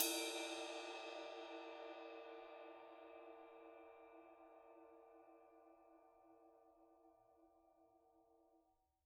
Percussion
susCymb1-hitstick_f_rr2.wav